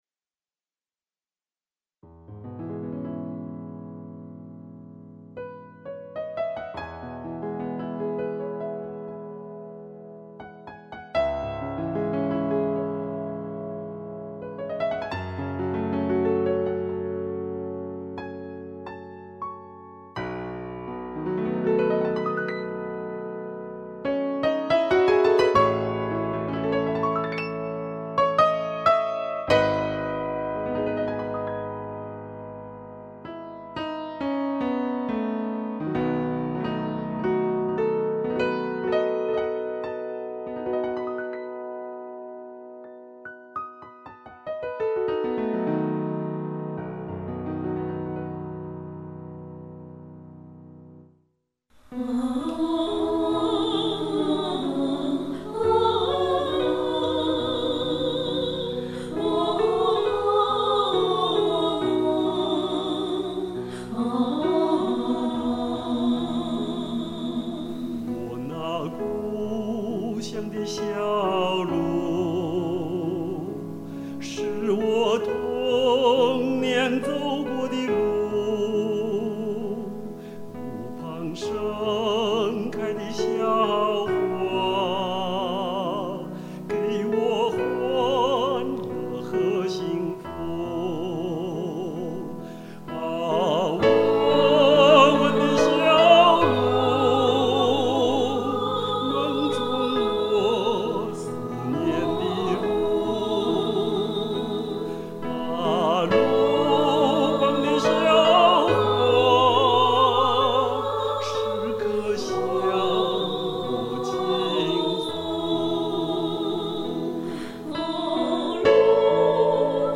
伴唱
钢琴伴奏